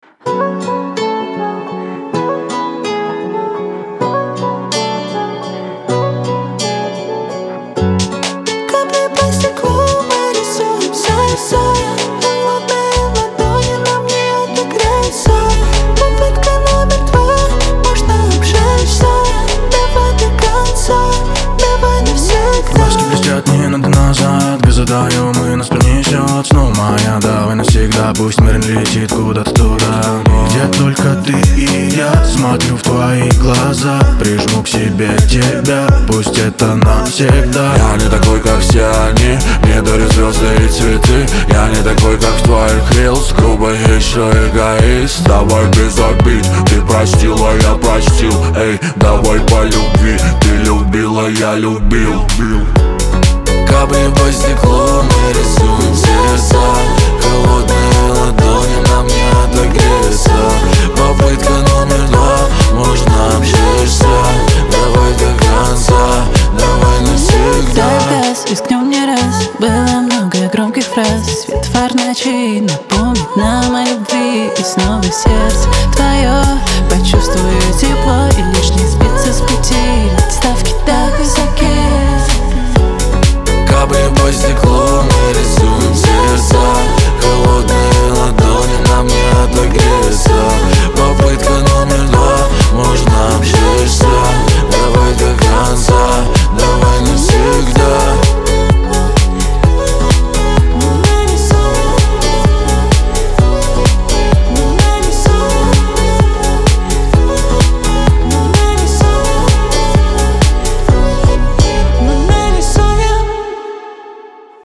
ХАУС-РЭП , pop
дуэт